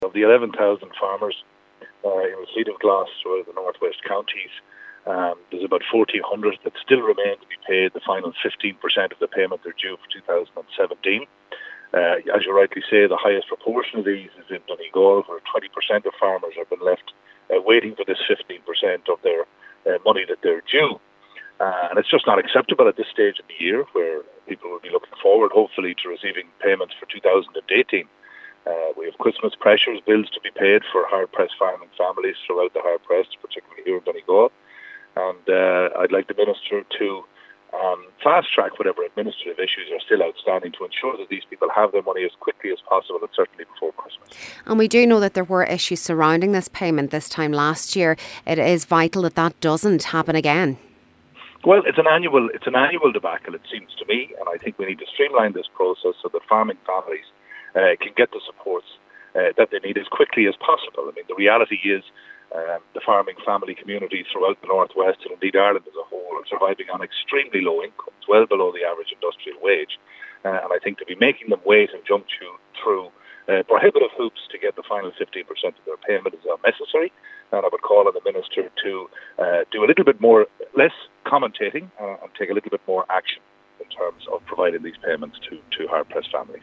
TD for South Donegal Deputy Marc Macsharry says delays in payments can have a huge impact on farming families and efforts must be made to address the issue: